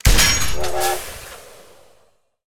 Wpn_riflerailway_fire_2d.ogg